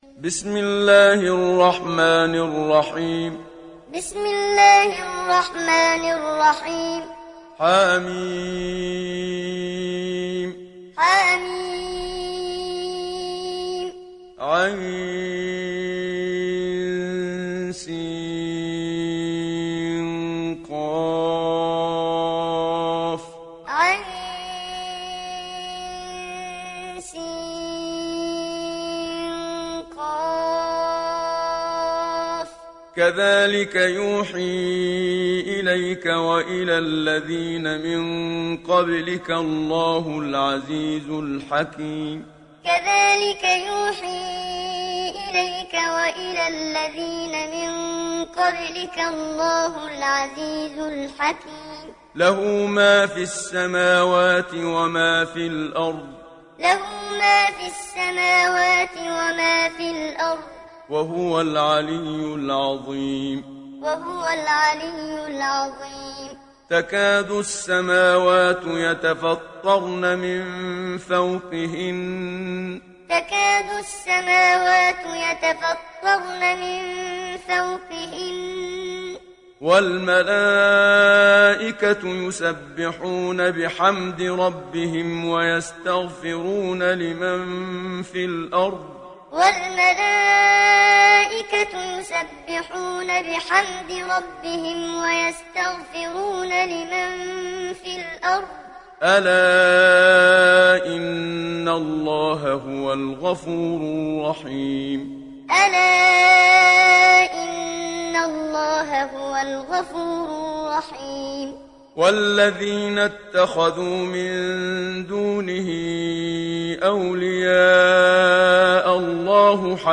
Surah Ash Shura Download mp3 Muhammad Siddiq Minshawi Muallim Riwayat Hafs from Asim, Download Quran and listen mp3 full direct links